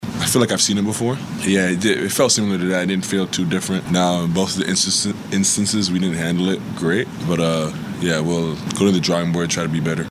After the contest Shai Gilgeous-Alexander talked about the way he was defended.